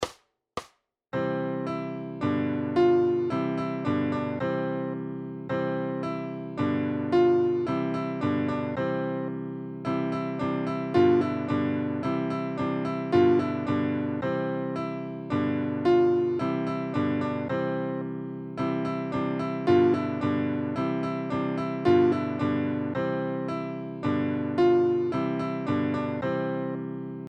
Koledy na 2 akordy
Aranžmá Noty na snadný sólo klavír
Hudební žánr Vánoční koledy